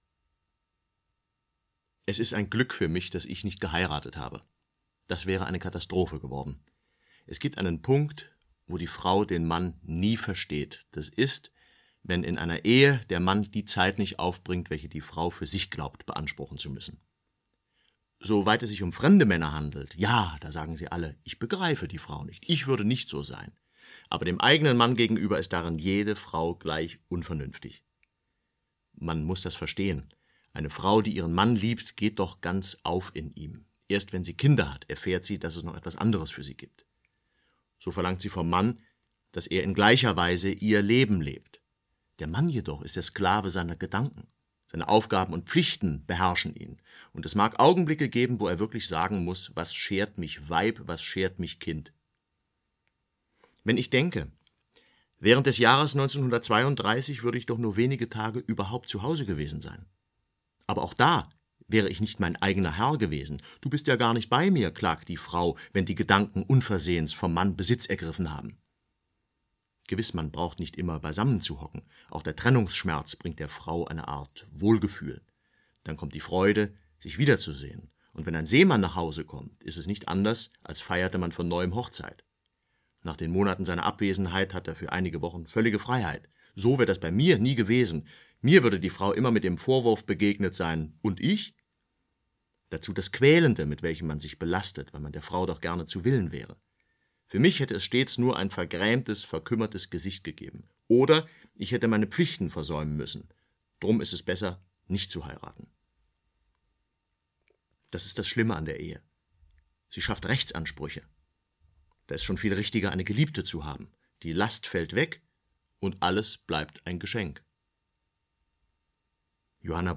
Hörbuch Die Sprache bringt es an den Tag - kostenlose Hörprobe, Adolf Hitler.